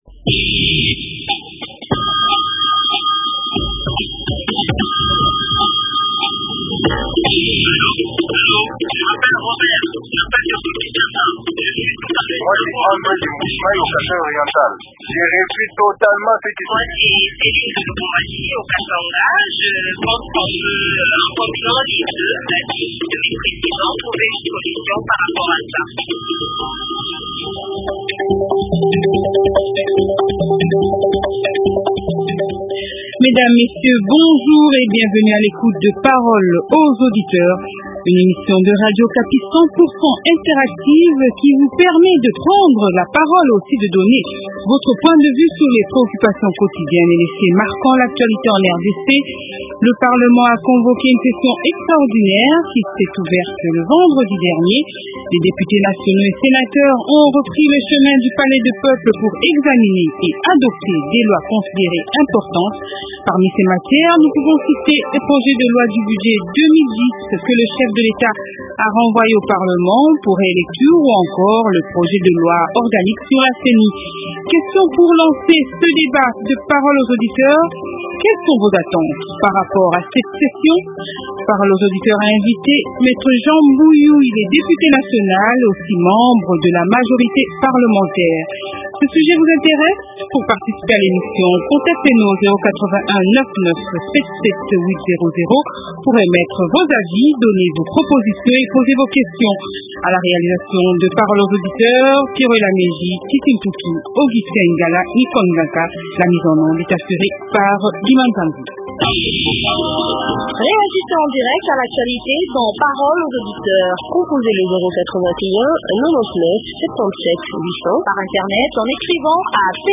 Jean Mbuyu, Député nationale, membre de la majorité parlementaire.